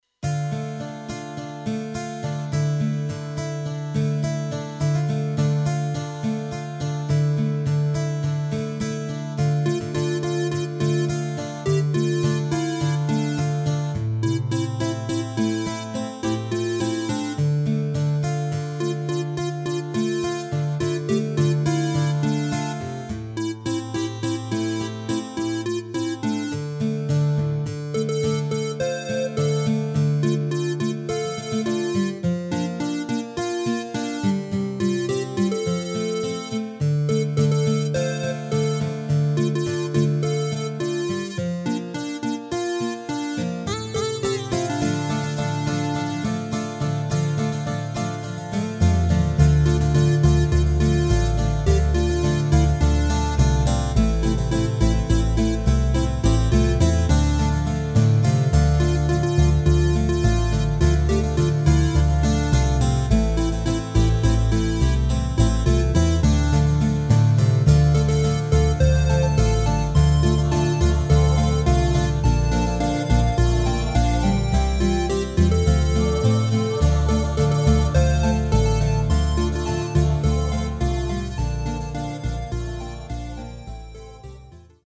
Rubrika: Folk, Country
HUDEBNÍ PODKLADY V AUDIO A VIDEO SOUBORECH